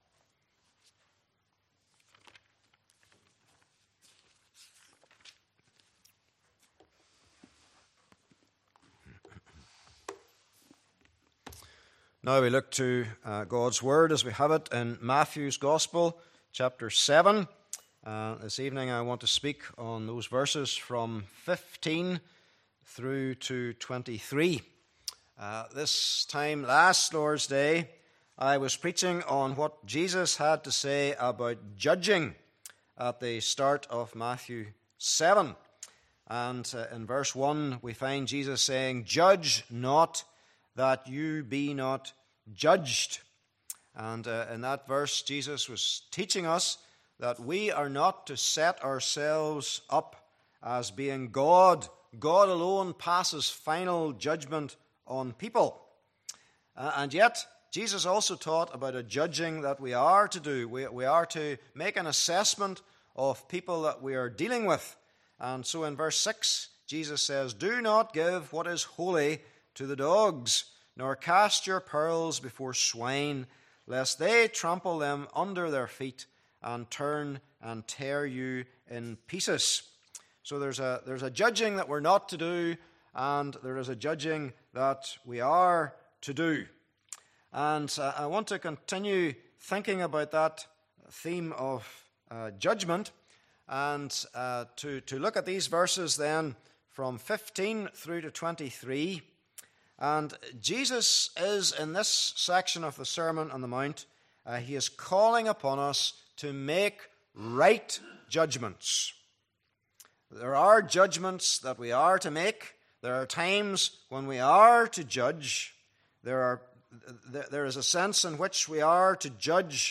Series: Sermon on the Mount
Service Type: Evening Service